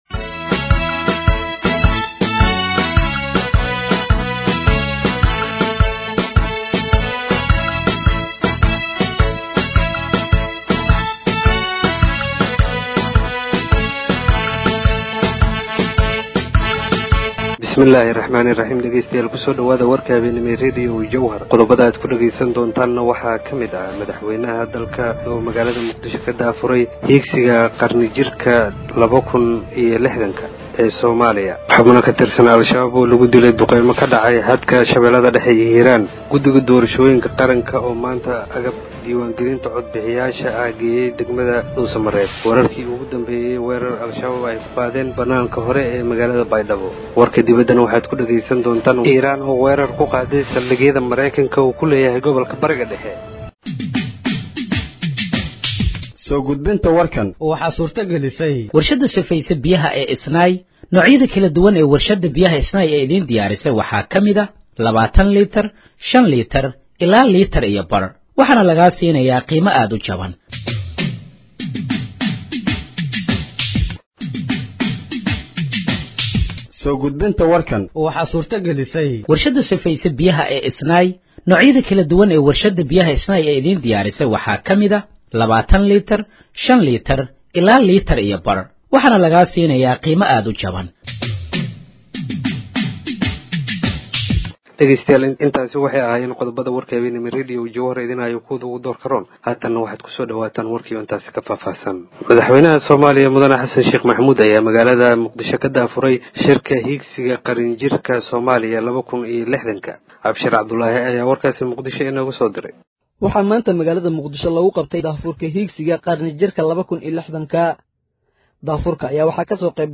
Halkaan Hoose ka Dhageeyso Warka Habeenimo ee Radiojowhar